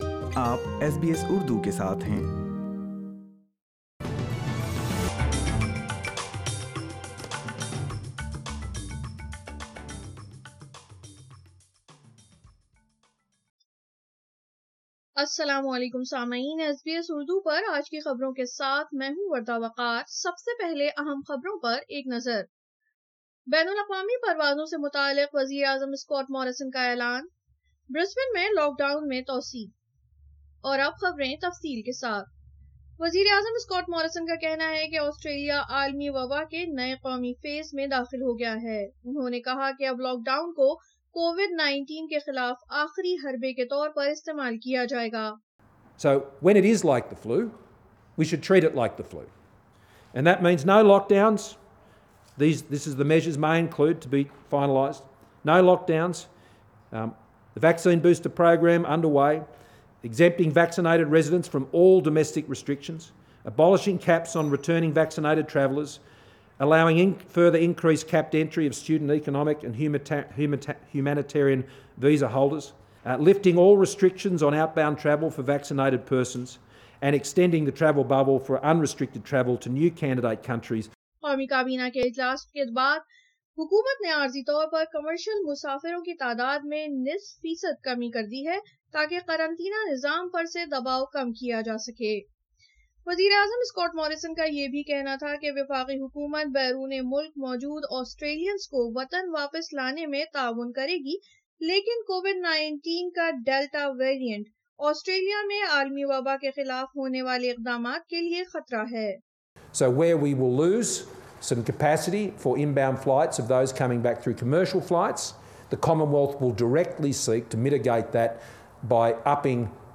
Prime Minister Scott Morrison announces changes to international arrivals for Australia. An extended lockdown for Brisbane. Listen Urdu News